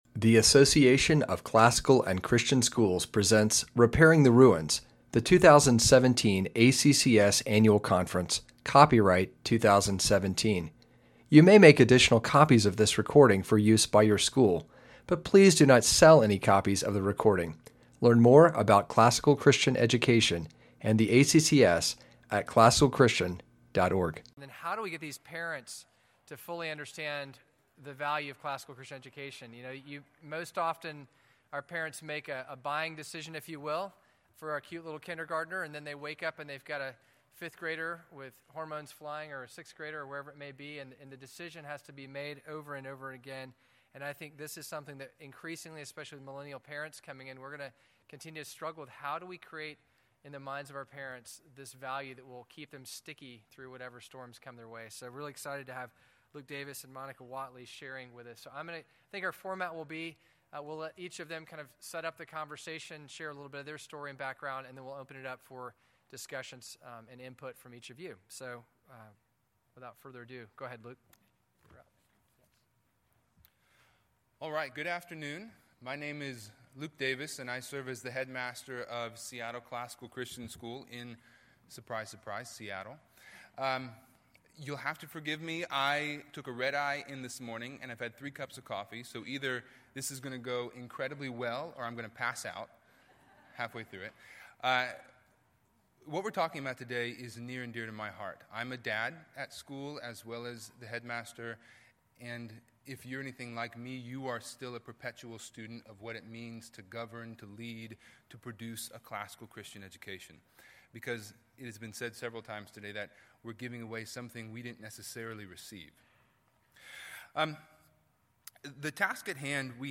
2017 Leaders Day Talk | 0:42:12 | Culture & Faith, Marketing & Growth